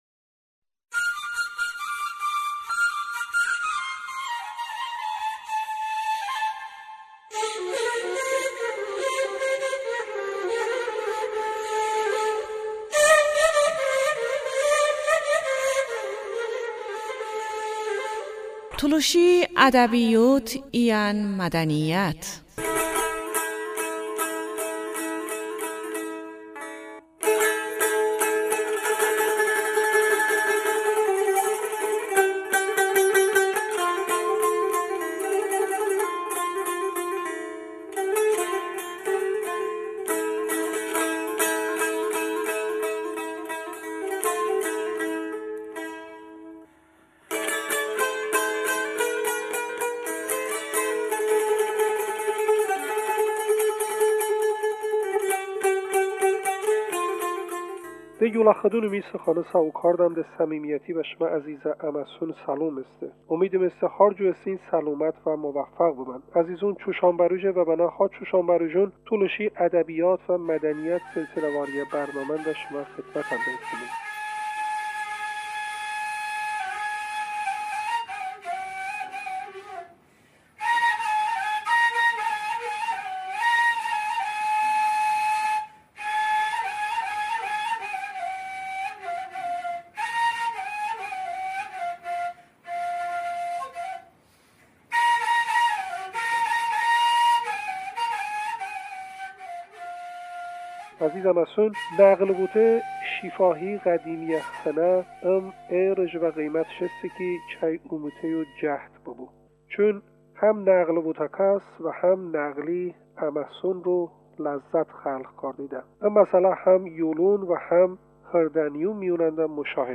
musahibə iyən tolışə nəğli bıməsən